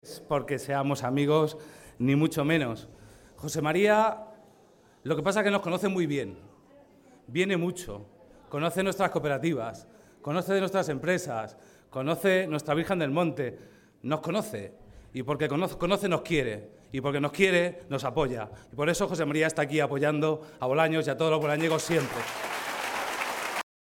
Por su parte el alcalde de Bolaños de Calatrava y candidato a la reelección, Eduardo del Valle, agradeció al jefe del Ejecutivo autonómico su implicación con Bolaños su apuesta por este municipio y el gran esfuerzo que se ha hecho desde la administración regional que ha aportado grandes sumas para mejorar los servicios públicos de la localidad.